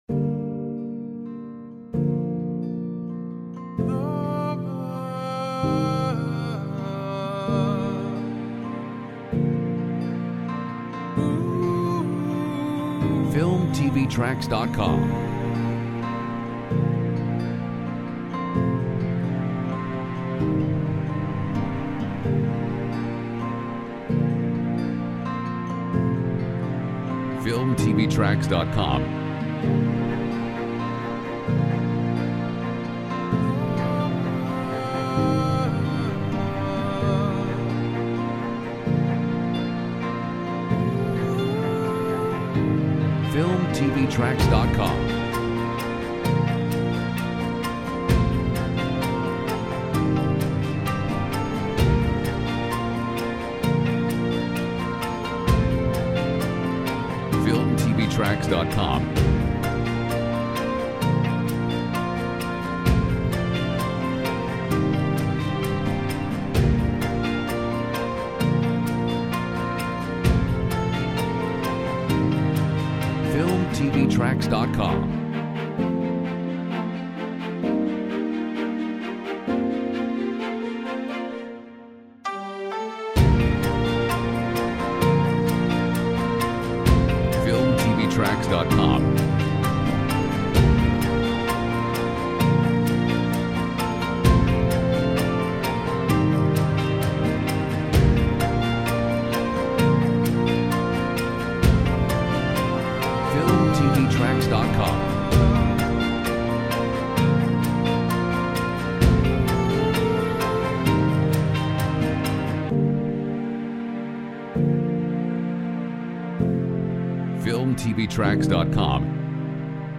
Genres: Piano, Orchestral
Mood: Calm, inspiring, uplifting, emotive